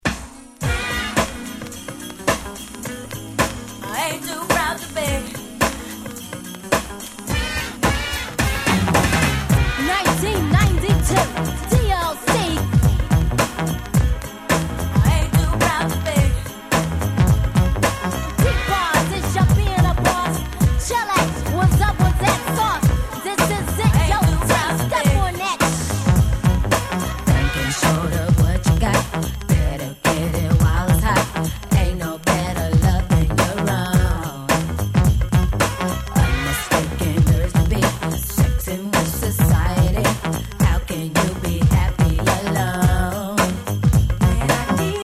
90's R&B Classics !!